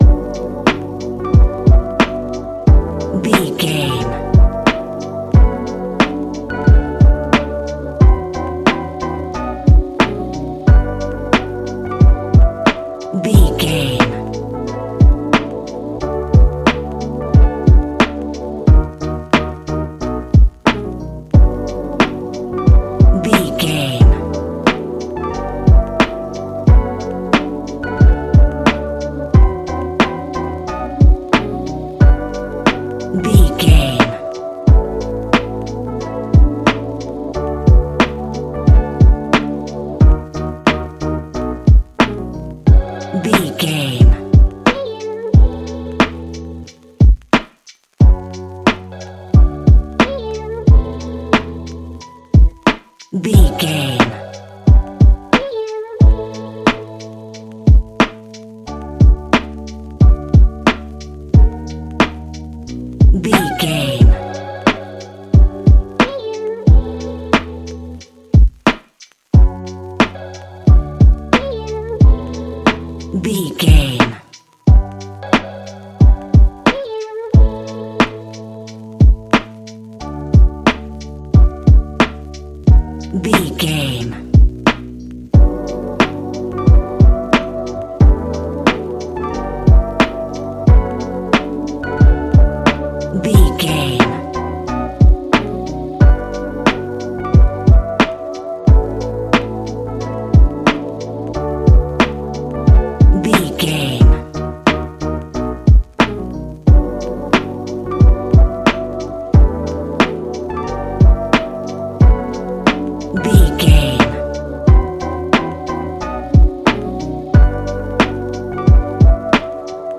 Ionian/Major
D♭
laid back
Lounge
sparse
new age
chilled electronica
ambient
atmospheric